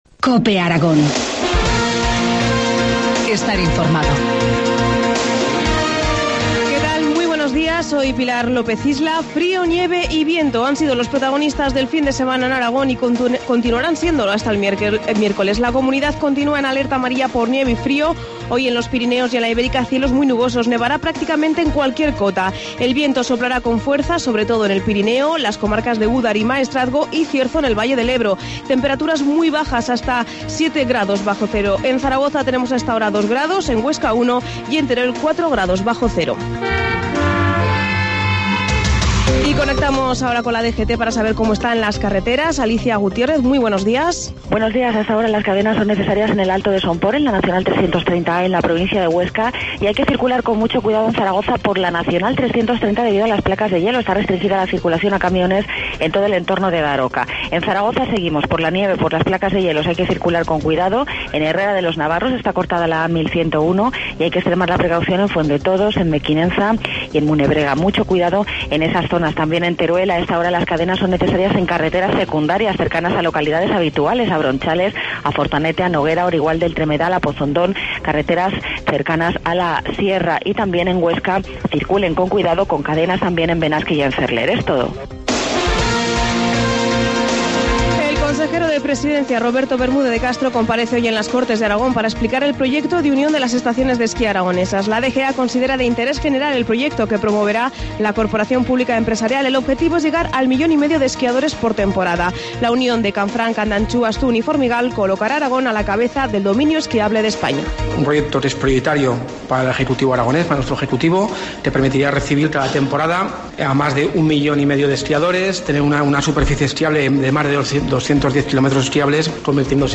Informativo matinal, lunes 25 de febrero, 7.25 horas